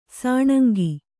♪ sāṇangi